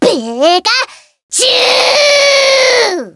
Tiếng Pikachu giận dữ dễ thương
Thể loại: Âm thanh vui nhộn
Description: Trên là hiệu ứng âm thanh tiếng Pikachu giận dữ dễ thương, tiếng Pikachu giận dữ và giọng nói dễ thương... Với hiệu ứng âm thanh này bạn có thể sử dụng để cài nhạc chuông dễ thương cho điện thoại hoặc dùng để dựng phim, ghép vào video...
Tieng-pikachu-gian-du-de-thuong-www_tiengdong_com.mp3